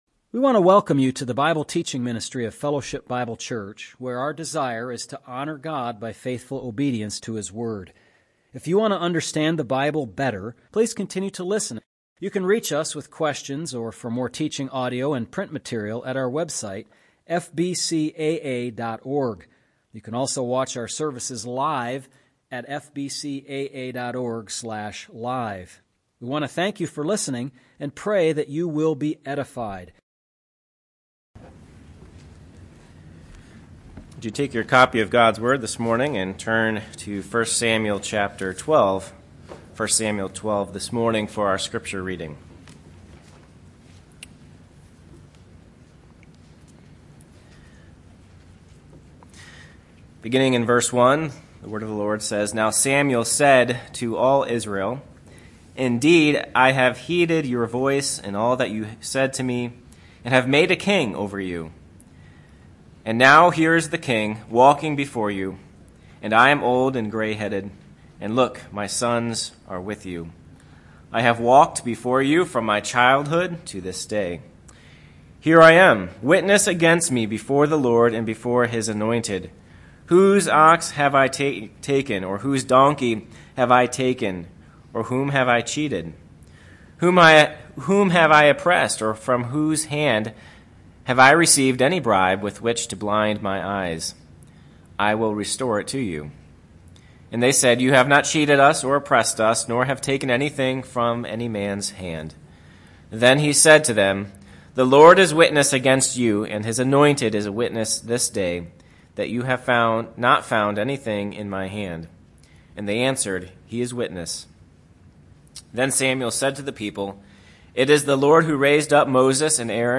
MP3 recordings of sermons and Bible studies for the Sunday ministries at the church.
From the Pulpit...